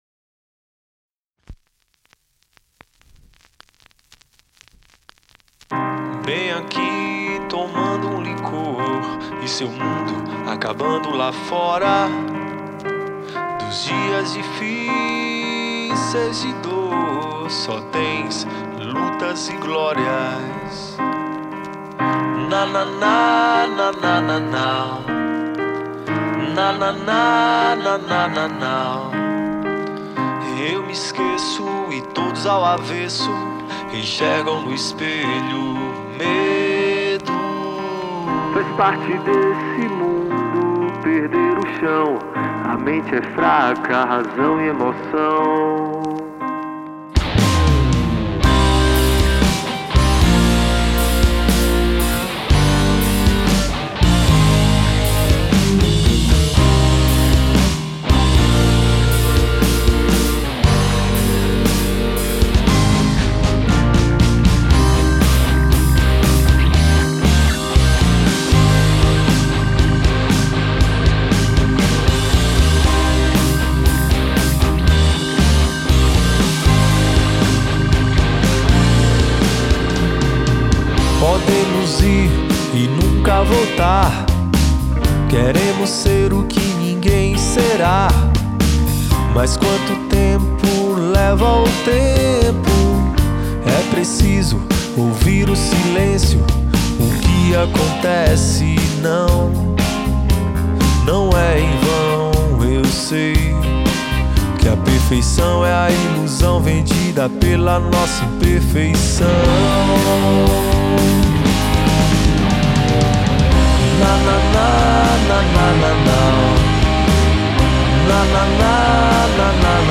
banda de rock